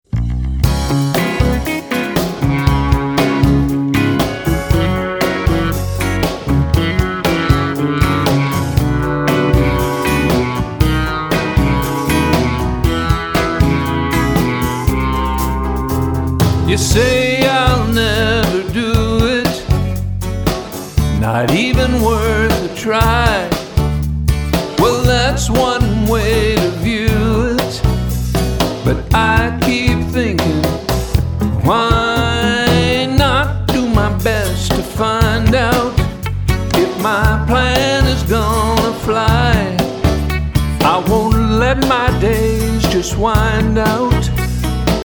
clever, cheeky, laugh-out-loud funny songs